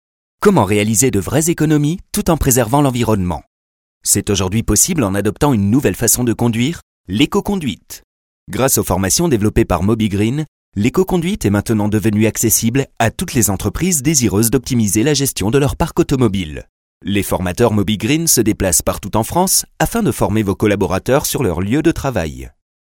Le timbre de ma voix est médium, jeune.
Sprechprobe: Industrie (Muttersprache):
french voice actor, medium voice